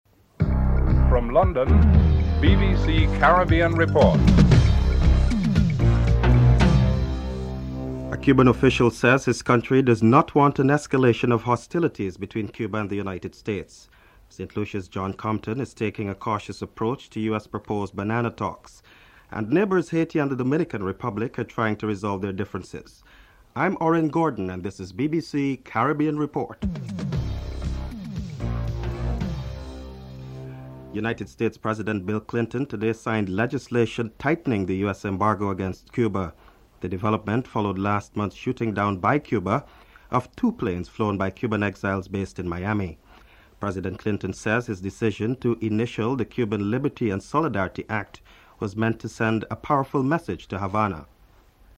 1. Headlines (00:00-00:30)
Prime Minister John Compton is interviewed